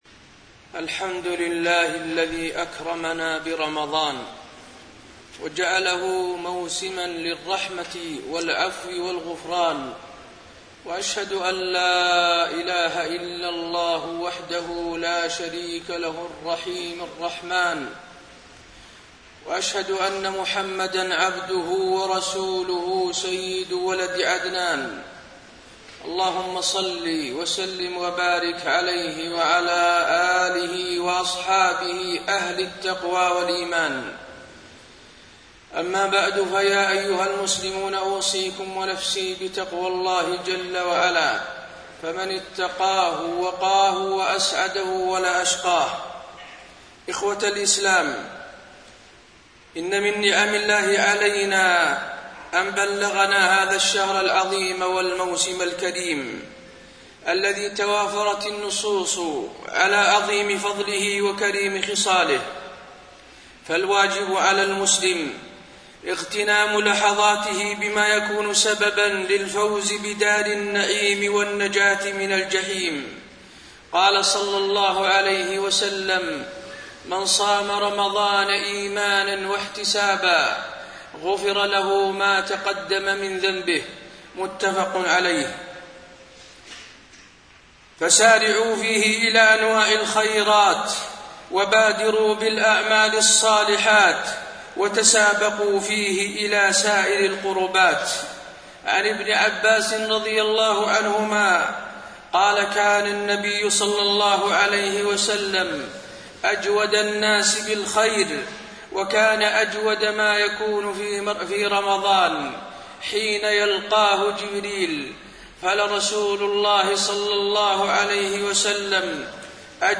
تاريخ النشر ٥ رمضان ١٤٣٢ هـ المكان: المسجد النبوي الشيخ: فضيلة الشيخ د. حسين بن عبدالعزيز آل الشيخ فضيلة الشيخ د. حسين بن عبدالعزيز آل الشيخ الحفاظ على الطاعات بعد رمضان